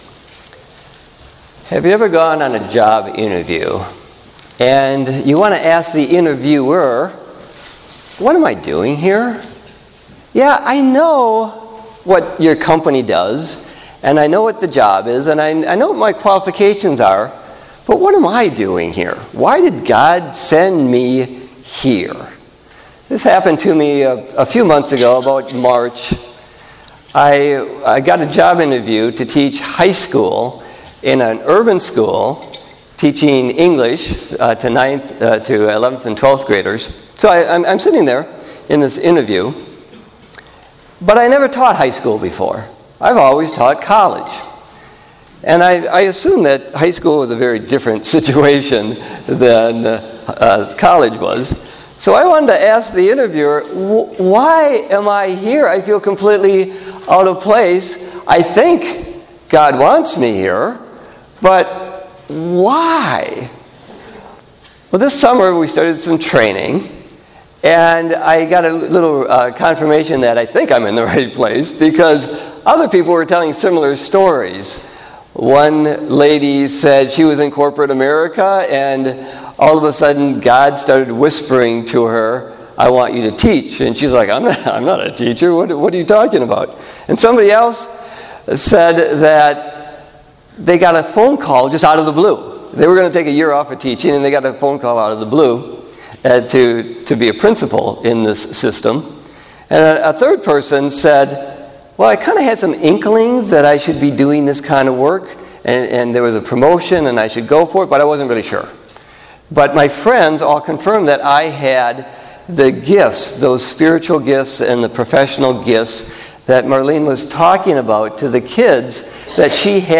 Sunday-8-19-18-Worship-Service.mp3